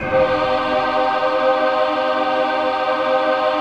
VOICEPAD05-LR.wav